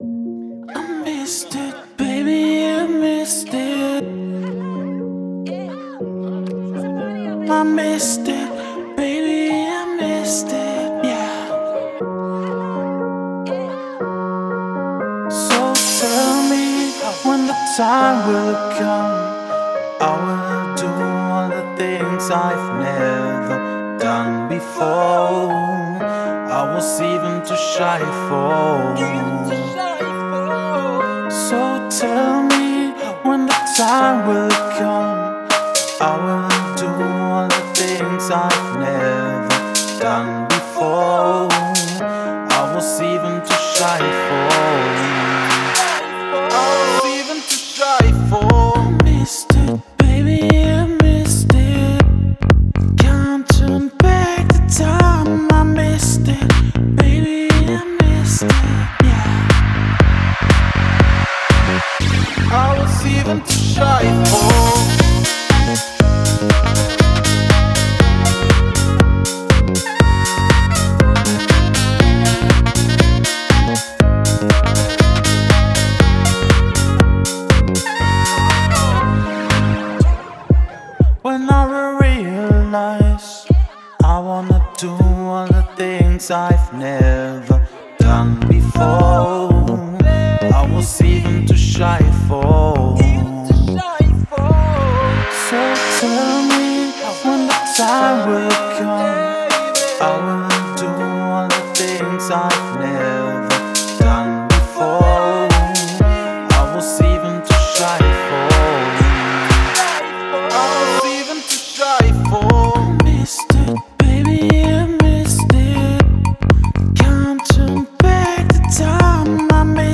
это энергичная поп-песня с элементами синти-попа